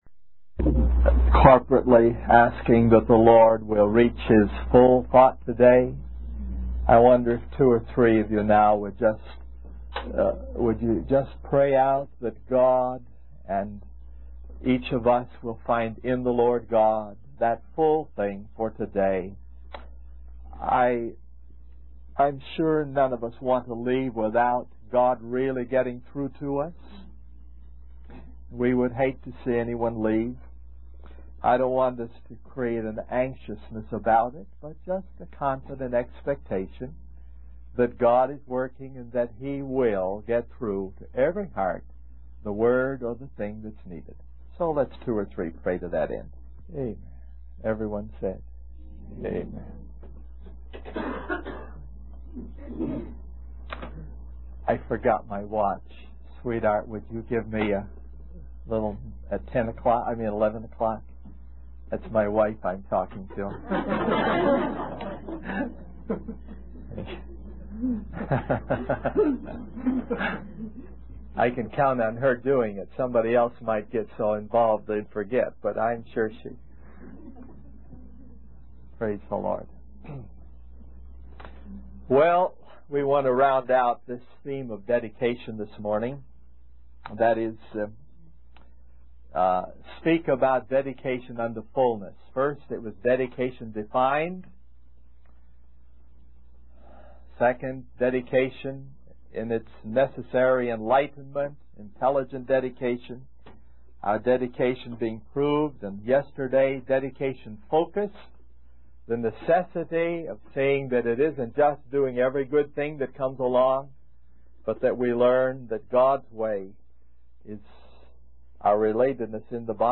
In this sermon, the preacher discusses the concept of dedication in the context of the word of God. He emphasizes the challenges faced by those who dare to step out of line and pioneer new paths in their faith.